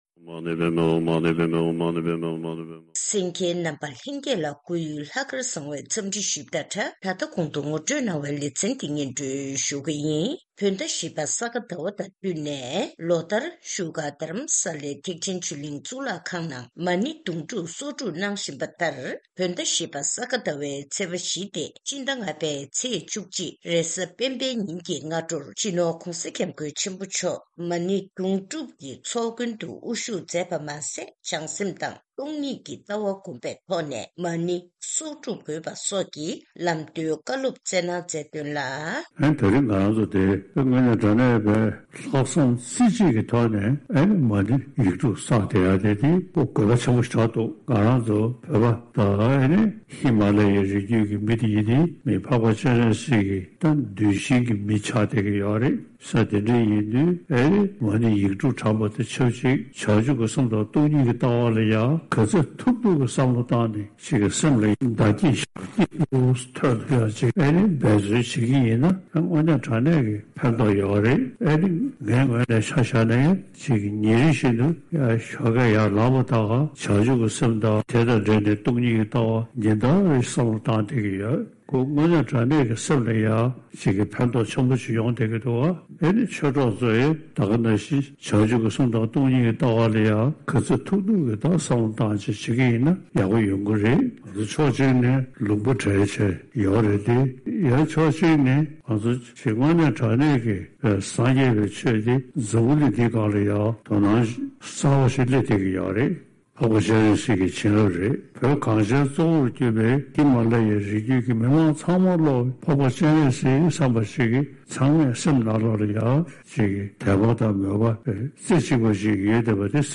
གནས་འདྲི་ཞུས་ནས་གནས་ཚུལ་ཕྱོགས་བསྒྲིགས་ཞུས་པ་ཞིག་གསན་རོགས་གནང་།།